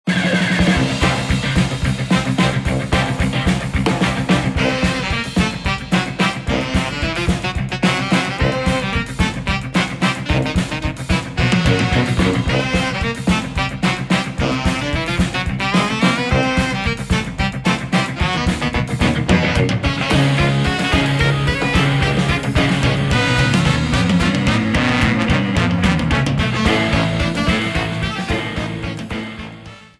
Shortened, applied fade-out, and converted to oga